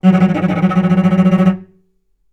healing-soundscapes/Sound Banks/HSS_OP_Pack/Strings/cello/tremolo/vc_trm-F#3-mf.aif at cc6ab30615e60d4e43e538d957f445ea33b7fdfc
vc_trm-F#3-mf.aif